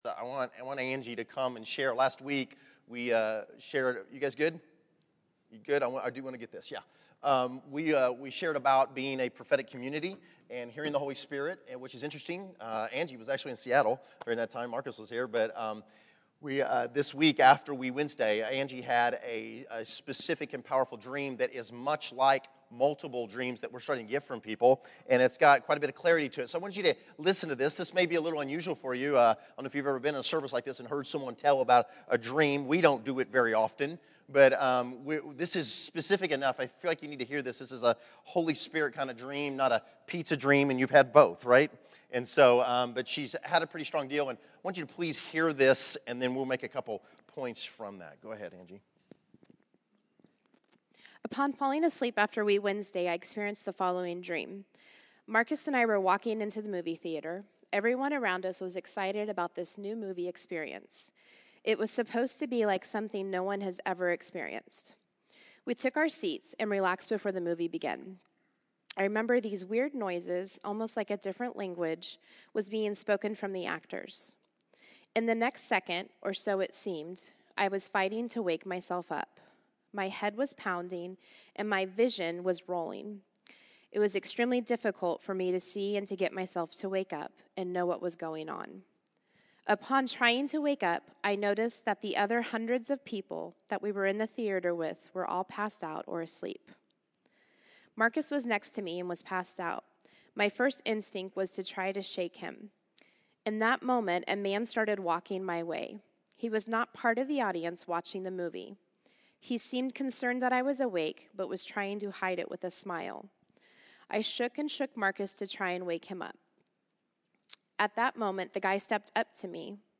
Category: Sermons | Location: El Dorado